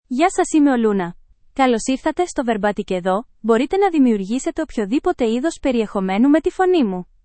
LunaFemale Greek AI voice
Luna is a female AI voice for Greek (Greece).
Voice sample
Listen to Luna's female Greek voice.
Luna delivers clear pronunciation with authentic Greece Greek intonation, making your content sound professionally produced.